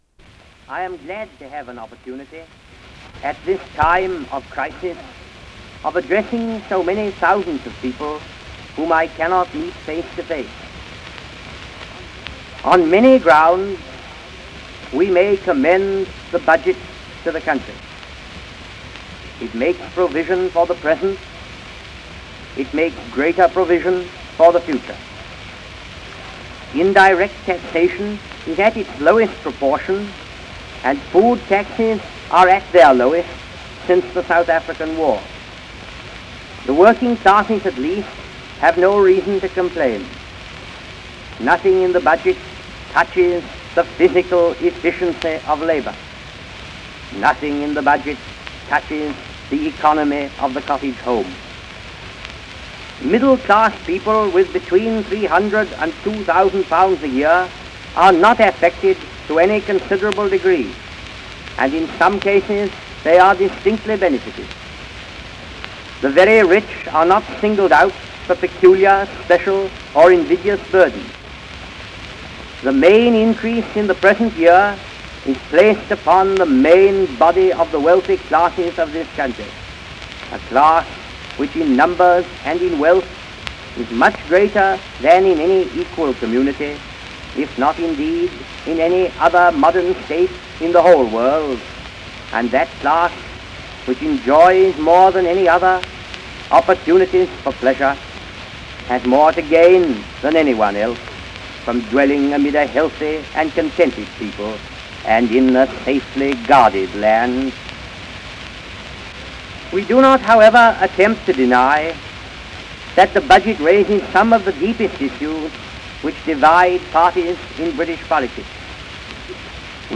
Winston Churchill delivers a message on the position of the Conservative party on budgetary matters